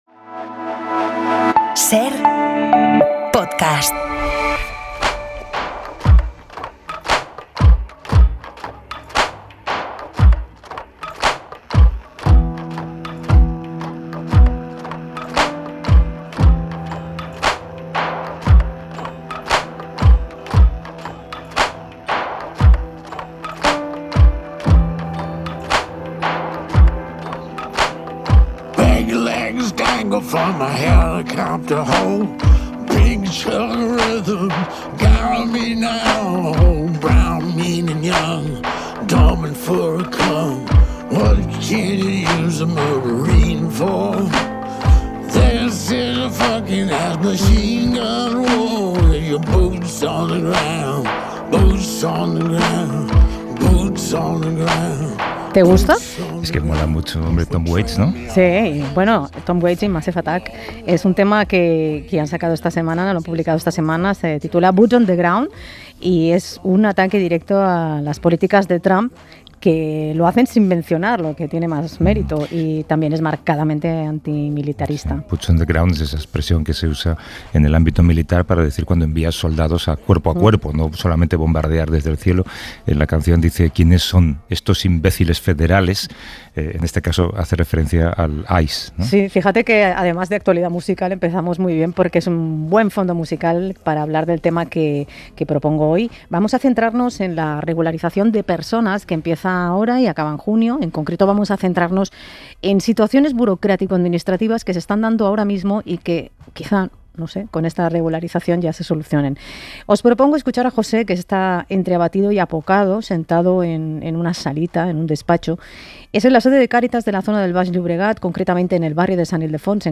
Hemos compartido una mañana en el centro de Cáritas del Baix Llobregat en el área metropolitana de Barcelona.